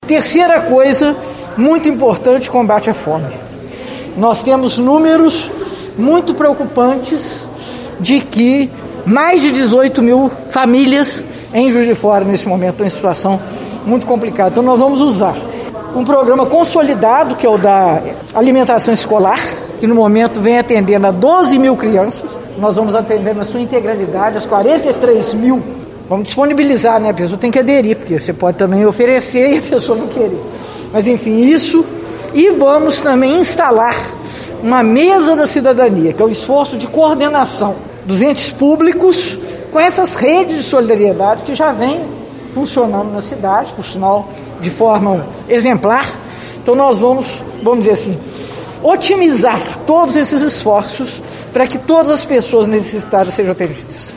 Em coletiva realizada nesta quarta-feira, 30, a prefeita eleita de Juiz de Fora, Margarida Salomão (PT) falou sobre os primeiros atos da nova administração.